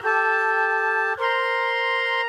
GS_MuteHorn_105-AC.wav